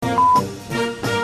I realize these "tones" are probably put in the song to keep folks from doing other things with it - that's fine.
Use a strong notch filter at 1 KHz.
tone.mp3